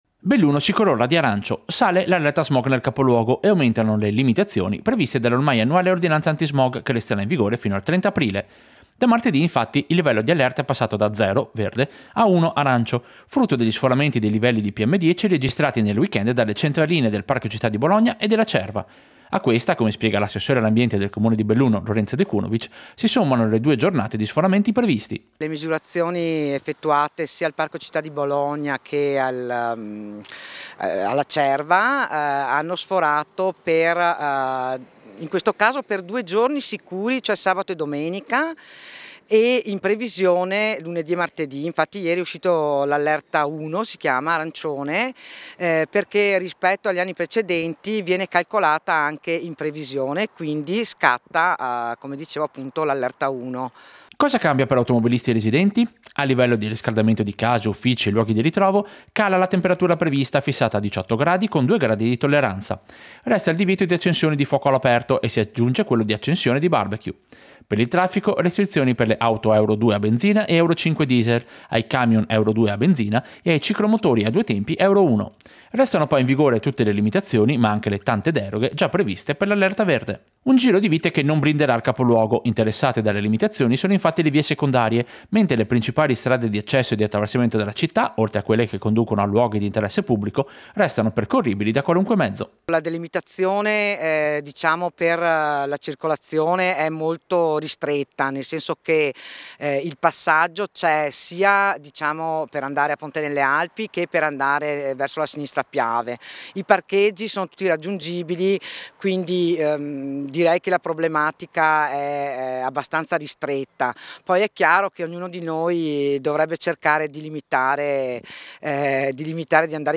Servizio-Allerta-smog-arancio-Belluno.mp3